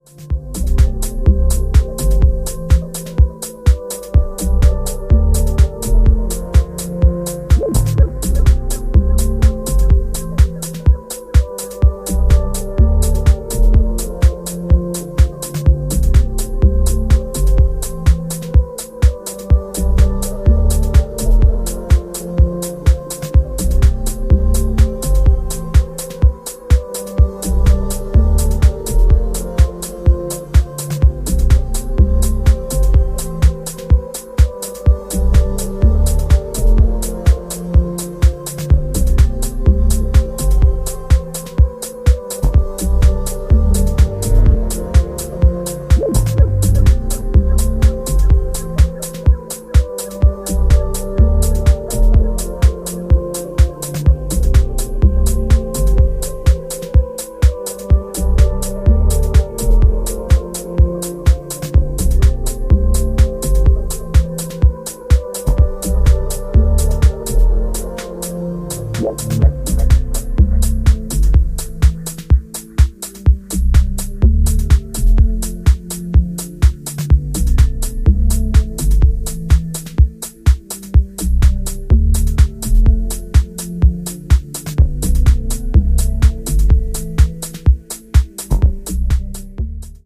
メランコリックなメロディーに意識も遠のく